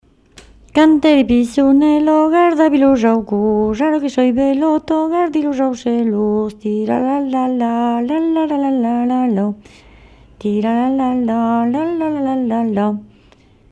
Lieu : Lodève
Genre : chant
Effectif : 1
Type de voix : voix de femme
Production du son : chanté
Danse : bourrée